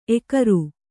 ♪ ekaru